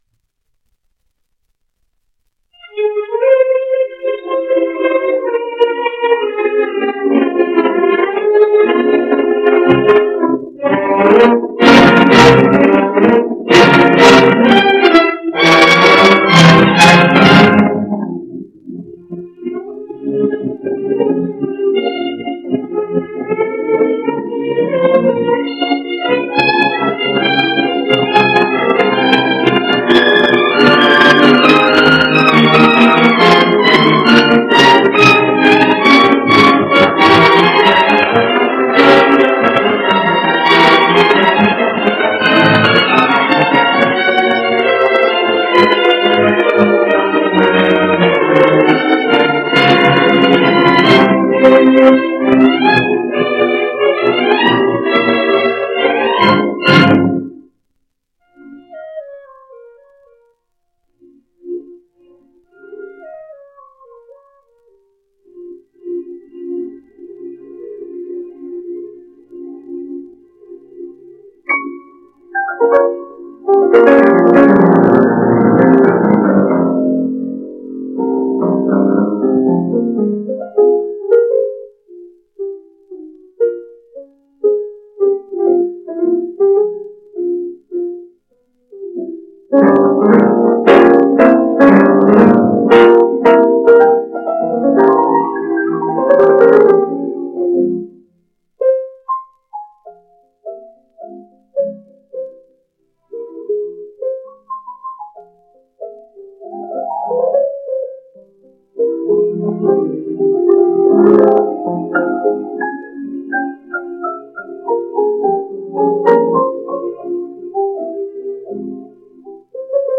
Concerto No. 2 in F minor, Op. 21. 1st movement (sonido mejorado)